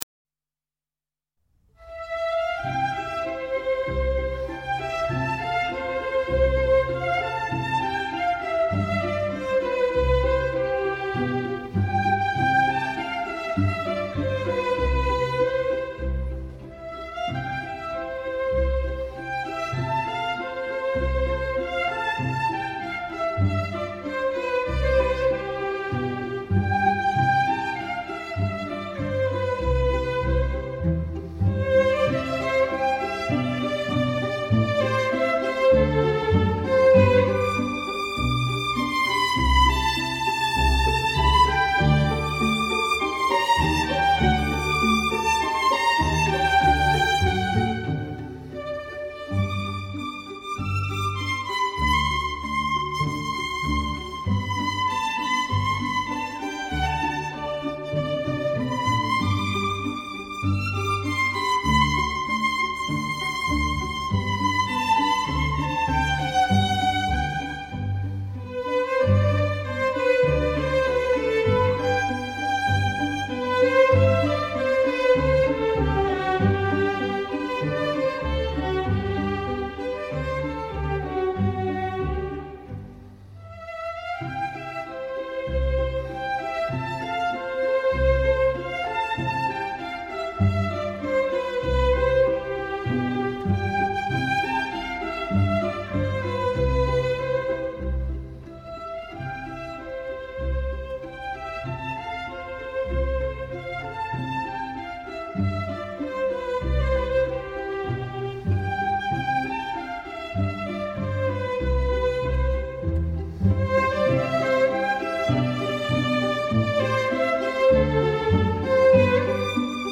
本曲用弦乐四重奏形式演出时，由第一小提琴加上弱音器奏出的主旋律流畅而亲切，充满了欢快的情绪。其他三个声部由第二小提琴，中提琴、大提琴用拔弦奏法奏出吉他伴奏的效果。
这首小夜曲色彩明朗，轻快的漫步节奏和娓娓动听的旋律，具有一种典雅质朴的情调，表现了无忧无虑的意境。